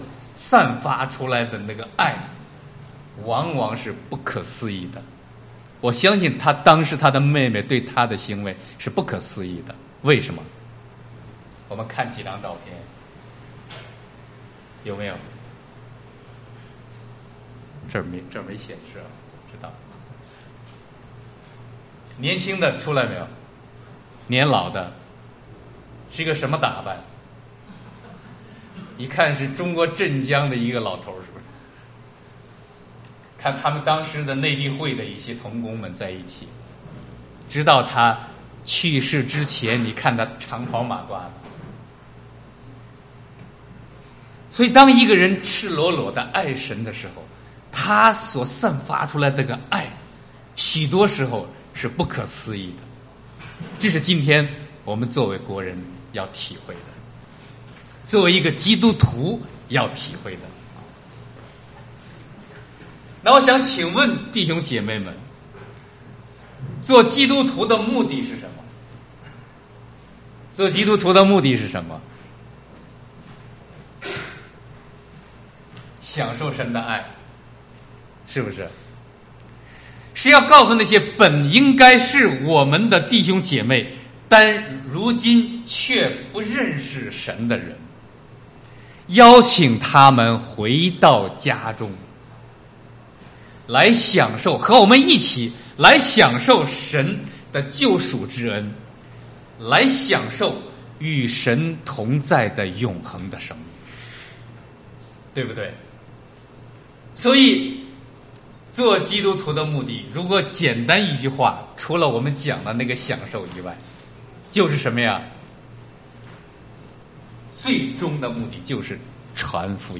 迎新福音佈道會 – 拉法葉華人宣道會
福音佈道信息